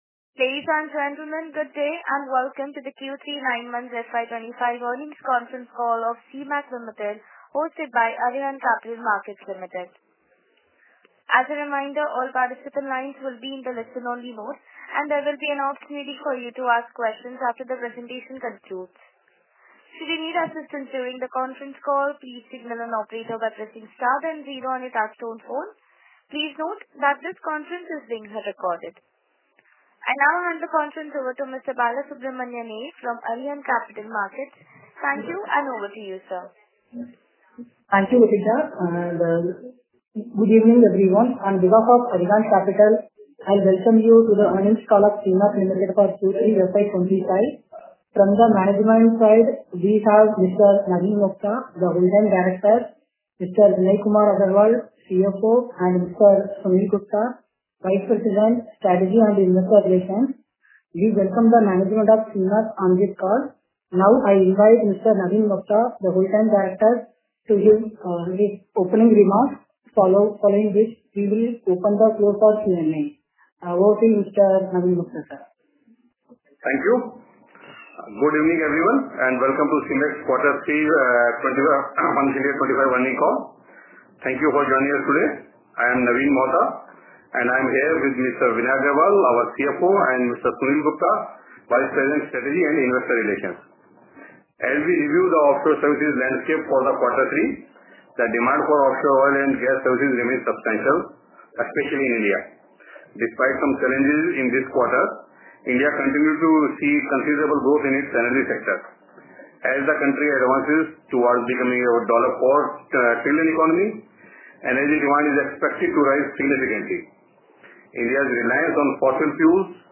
Earning Conference Call Audio Recording
Earning Concall Audio Recording for Q3 FY 2024-25